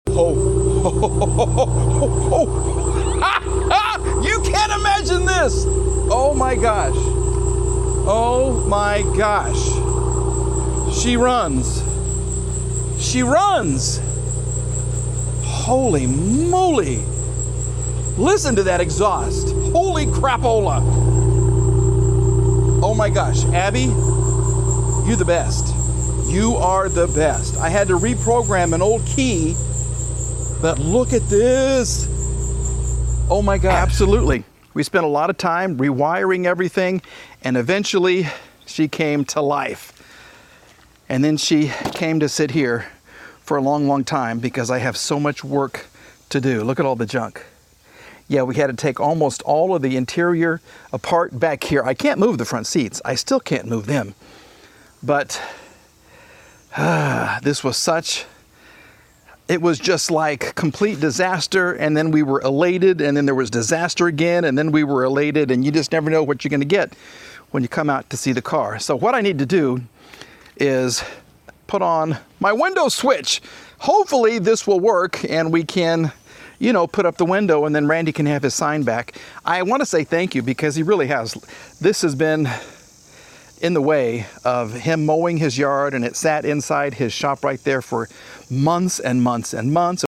🚗💨 The exhaust note?! 🎶 After months of work, she finally lives!